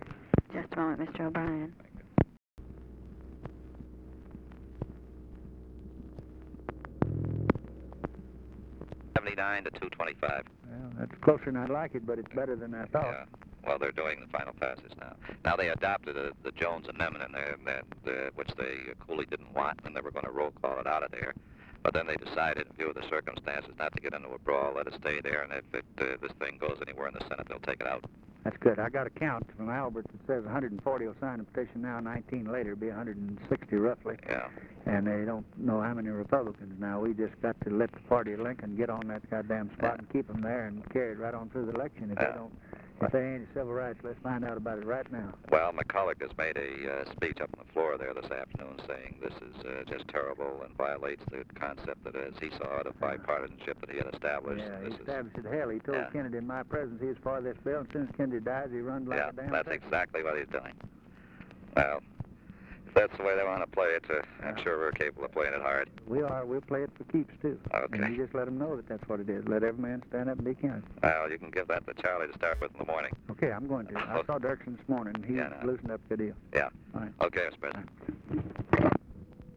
Conversation with LARRY O'BRIEN, December 4, 1963
Secret White House Tapes